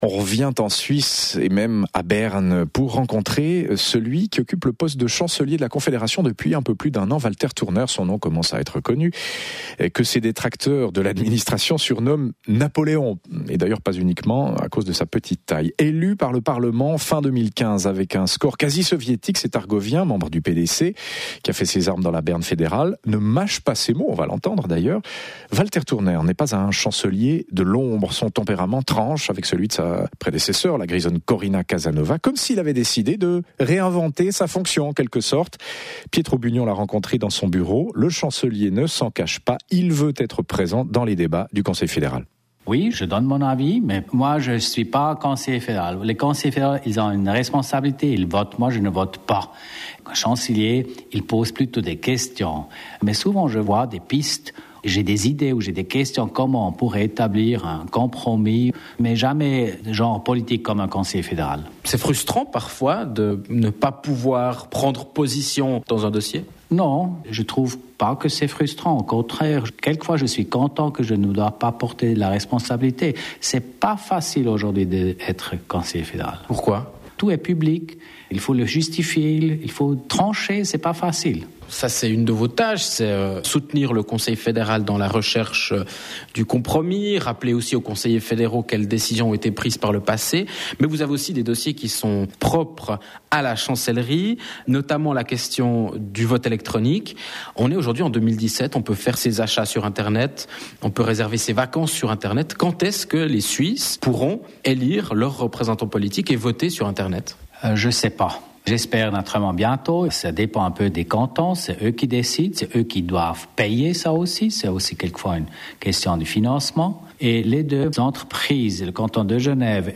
Elu par le parlement fédéral fin 2015, l’Argovien Walter Thurnherr, démocrate-chrétien au fort tempérament, entend être présent dans les débats du Conseil fédéral. Son interview par la RTS.